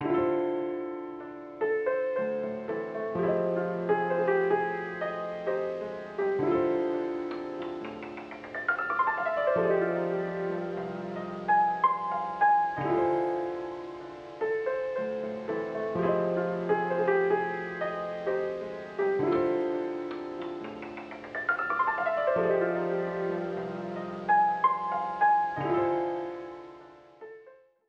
jazz keys 1.wav